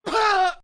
Screaming Dedman 7